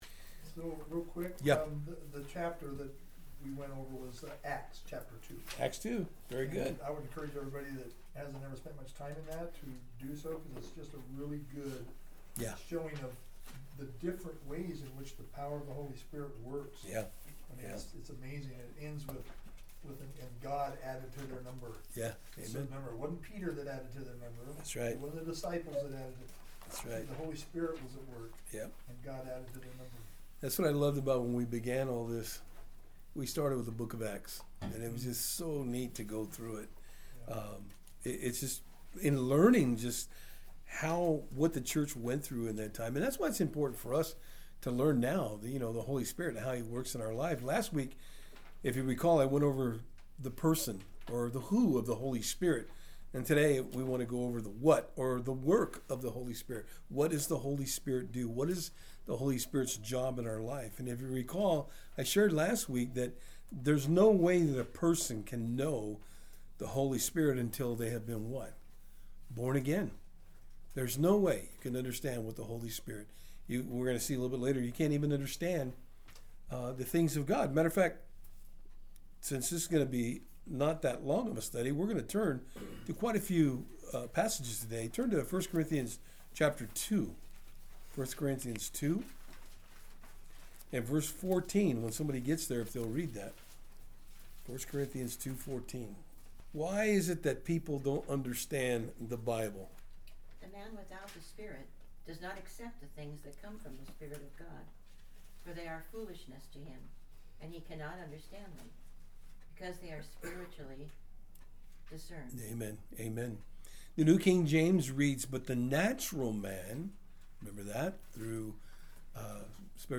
New Testement Service Type: Thursday Afternoon In this study we will go over the work of the Holy Spirit in the life of the believer.